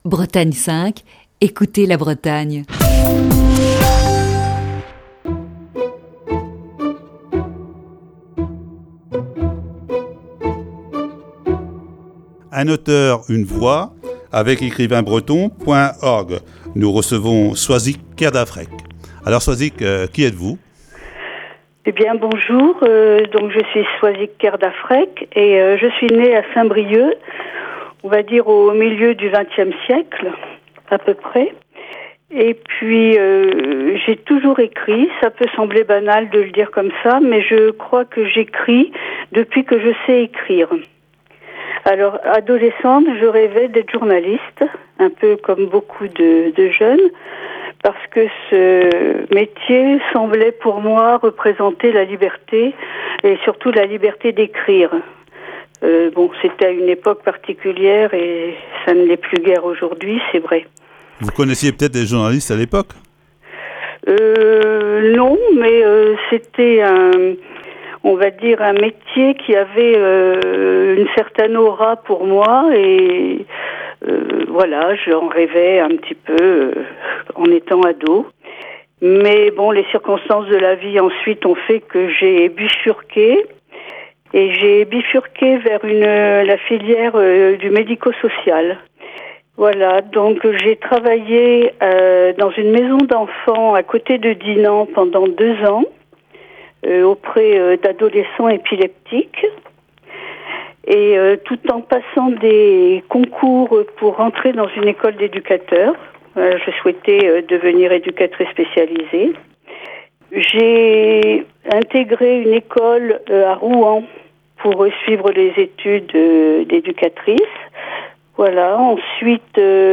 Voici ce lundi, la première partie de cet entretien.